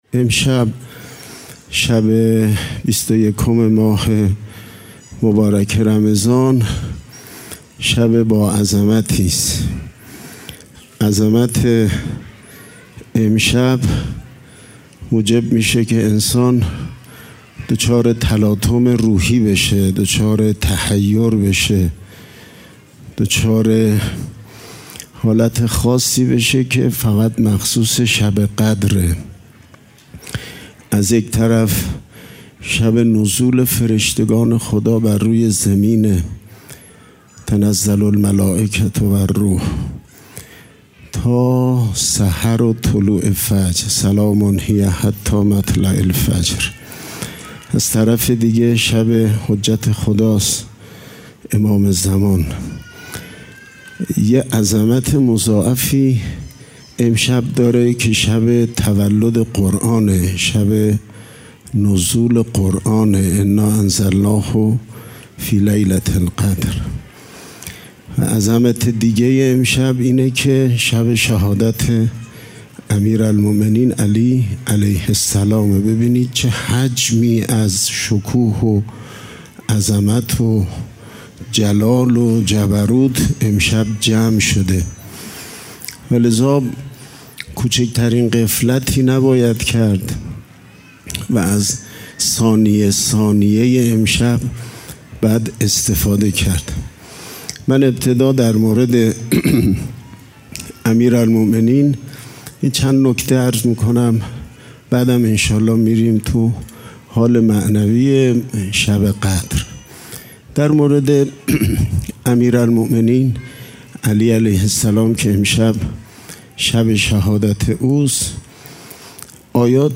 صوت شب 21 رمضان - شب قدر دوم